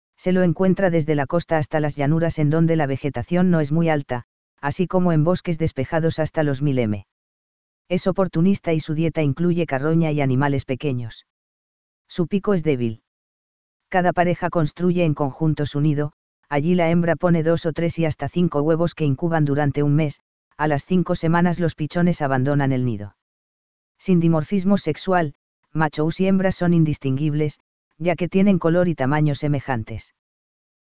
Chimango.mp3